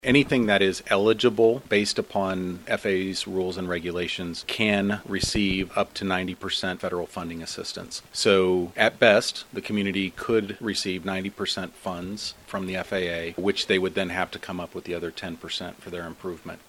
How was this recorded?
Manhattan Regional Airport Master Plan team holds public information workshop - News Radio KMAN